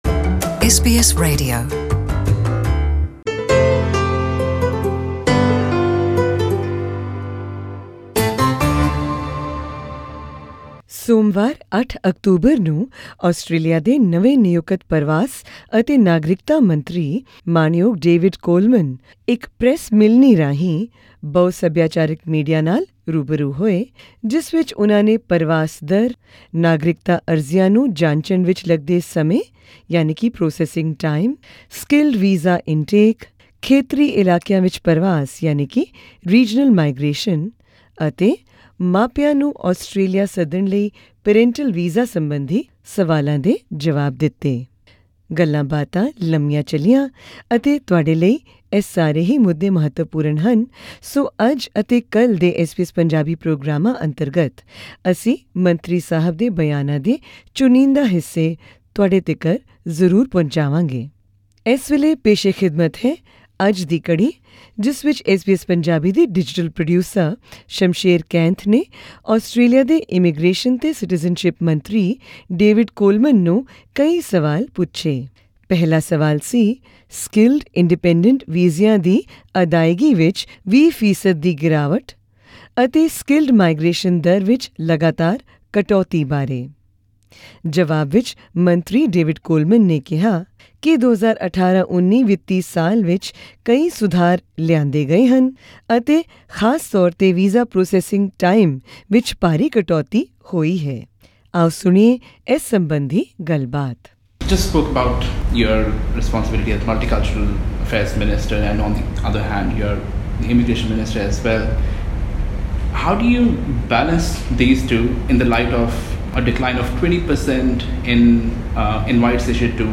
At an ethnic media press conference held in Melbourne early last week, Mr Coleman said that 150 new staff members had been hired by the Department of Home Affairs and processing times of many applications have been drastically reduced.